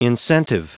Prononciation du mot incentive en anglais (fichier audio)
Prononciation du mot : incentive